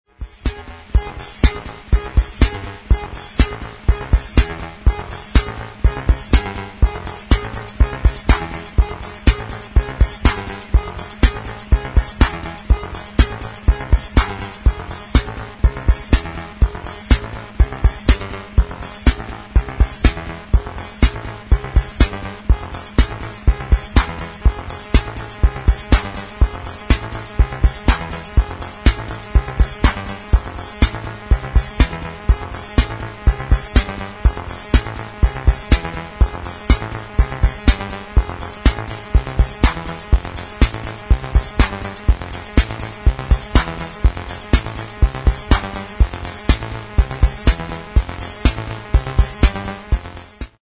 7 Deep smokers, laidback – heads down acid synth techno.
Genre Acid , Experimental , Techno